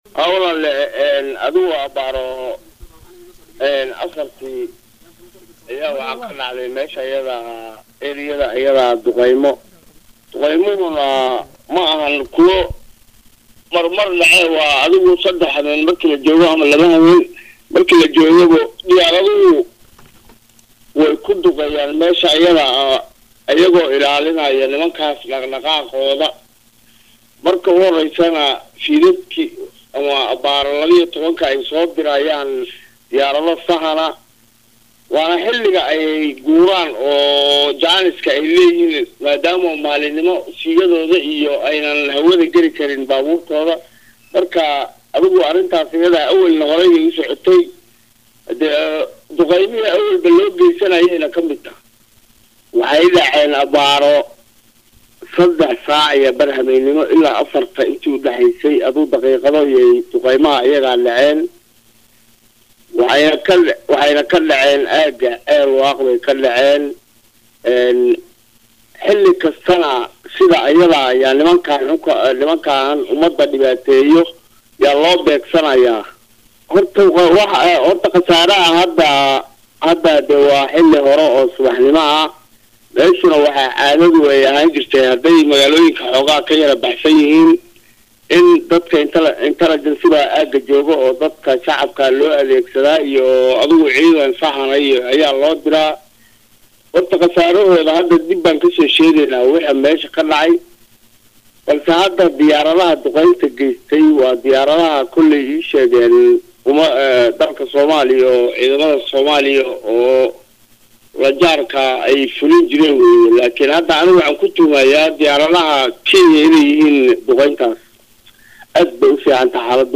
Halkaan ka Dhageyso Codka Gudoomiyaha
wareysi-gudoomiyaha-dagmada-ceel-waaq-xasan-cabdullhi-maxamed-.mp3-kkkkkk.mp3